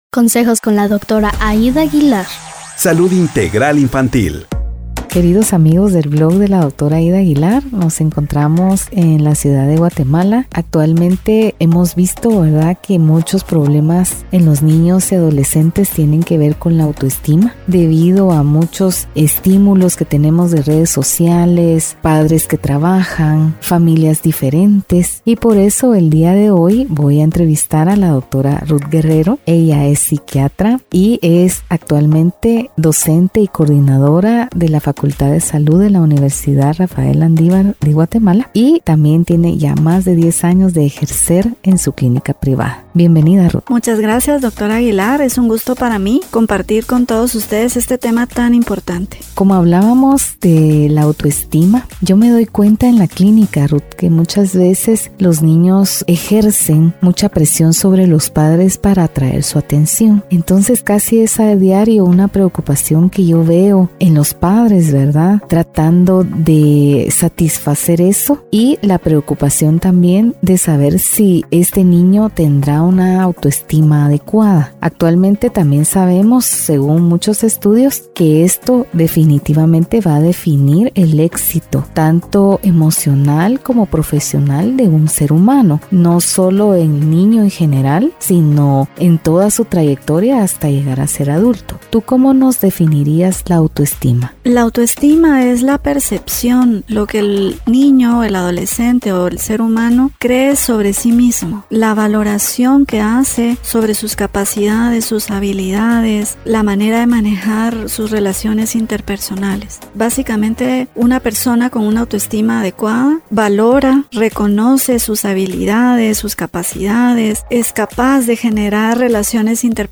Cómo Mejorar la Autoestima de Nuestros Hijos. Entrevista